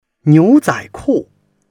niu2zai3ku4.mp3